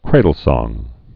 (krādl-sông, -sŏng)